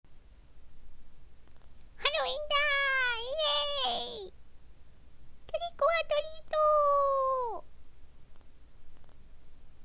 ガヤ③(使えそうだったら‥)